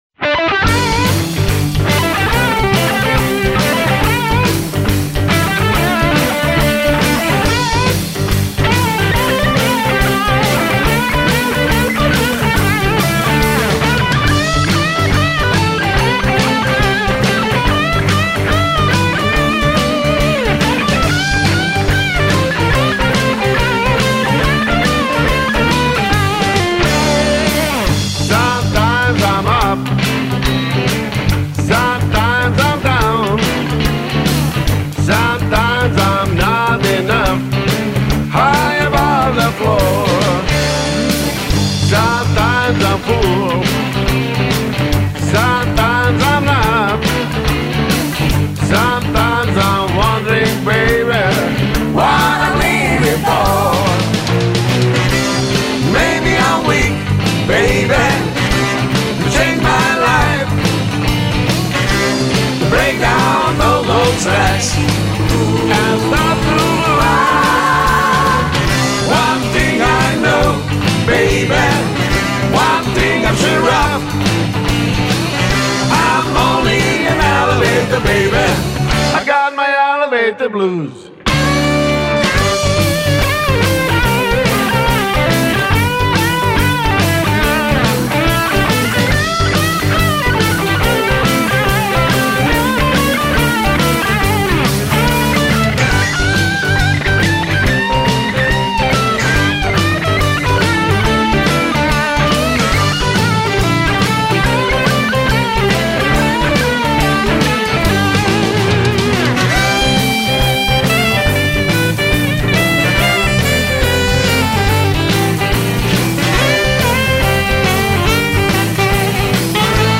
americana blues kvintet
saksofonistce
prateći vokali